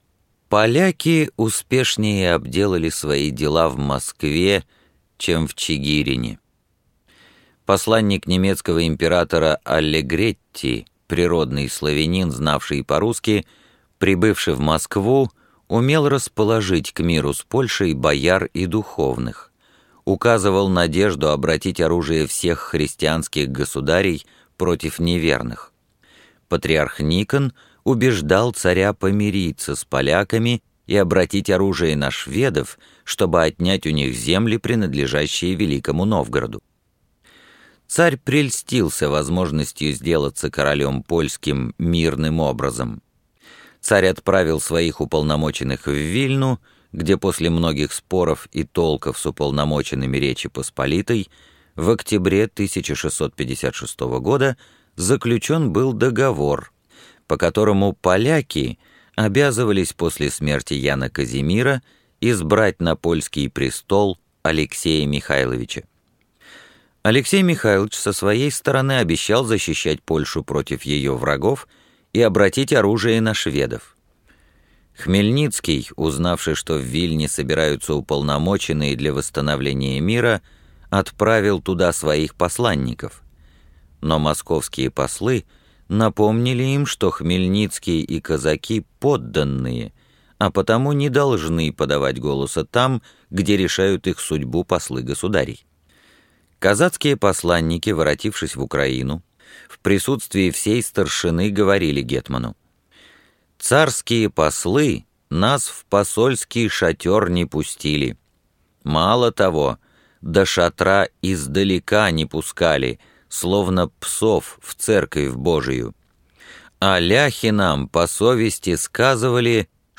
Аудиокнига Русская история. Том 9. От Богдана Хмельницкого до сибирских землеискателей VII века | Библиотека аудиокниг